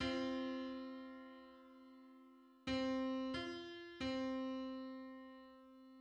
21:16 = 470.78 cents.
Other versions Image:Twenty-first_harmonic_on_C.png Licensing [ edit ] Public domain Public domain false false This media depicts a musical interval outside of a specific musical context.
Twenty-first_harmonic_on_C.mid.mp3